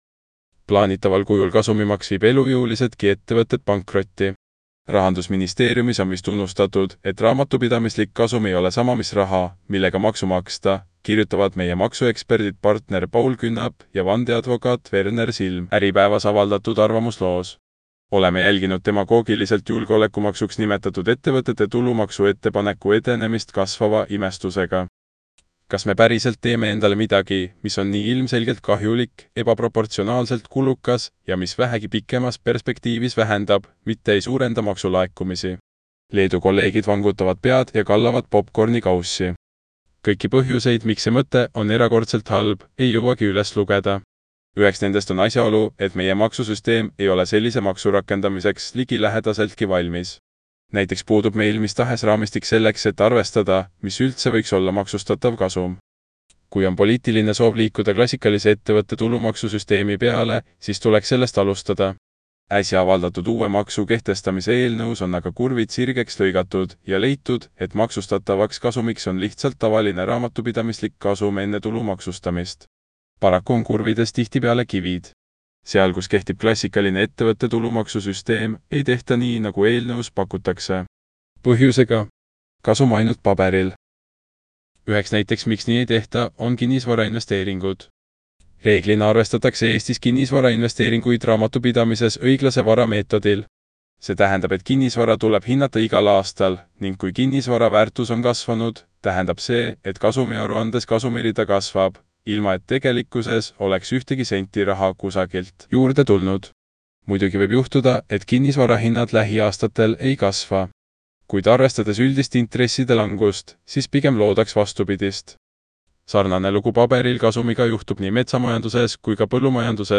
Kui silmad puhkust vajavad, anna artikkel üle kõnerobotile – vajuta ja kuula!